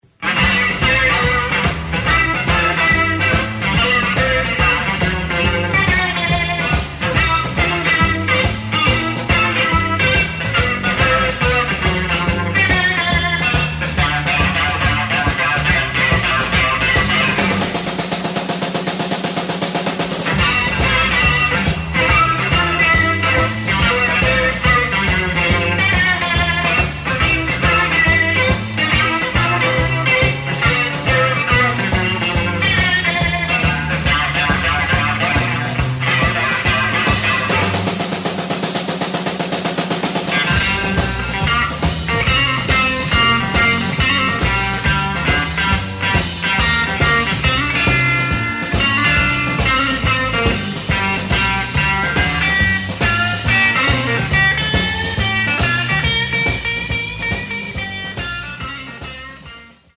Sång/Gitarr
Kontrabas
Trummor